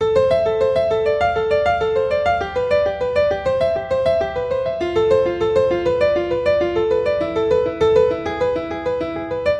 原声钢琴4100bpm
描述：D小调的HipHop/Rap钢琴循环曲。